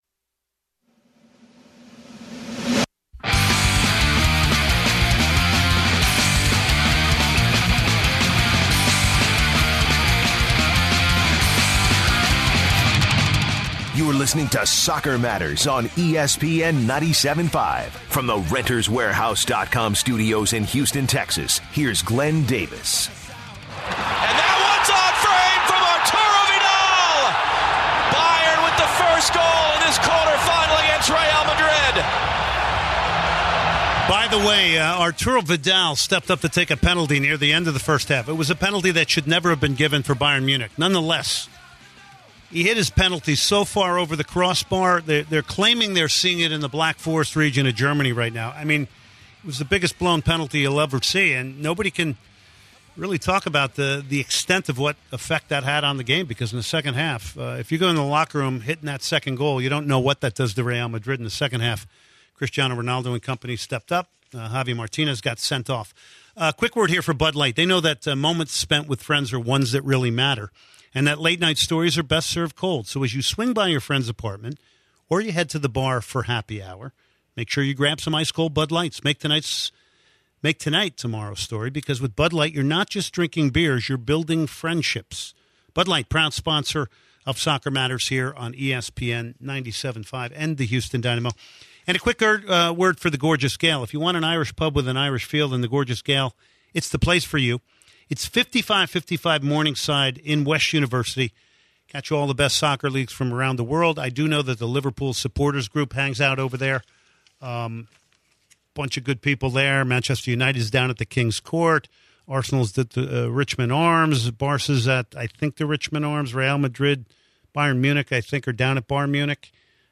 come in-studio to talk about the Houston Dash’s pre-season, coaching styles, new signings this season and the team’s system. To end the show, Carli Lloyd comes on to talk about her time in Manchester City, the differences between NWSL and WPL, and the continuing develo